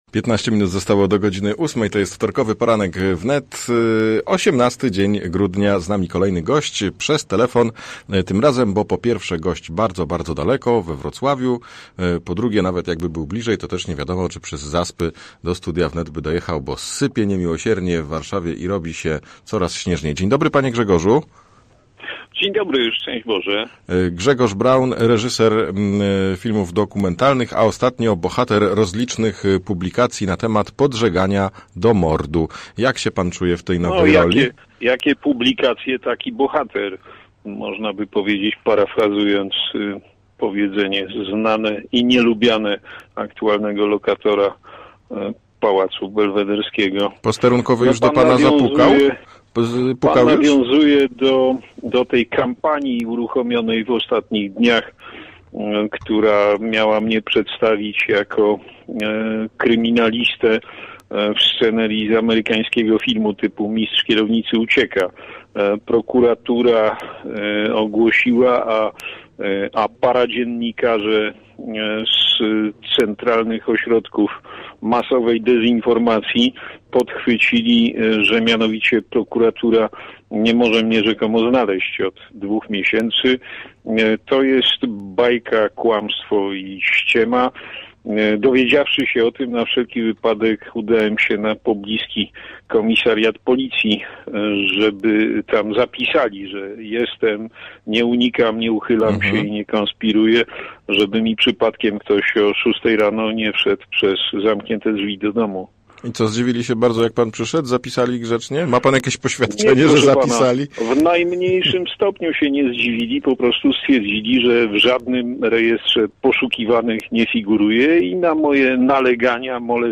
Jakie kolejne zarzuty usłyszał Grzegorz Braun? Jaka jest rola mediów? Posłuchaj całej rozmowy!